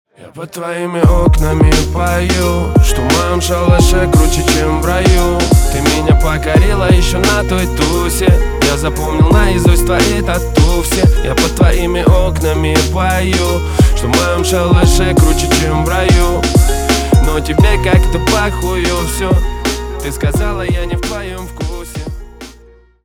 на русском на девушку про любовь грустные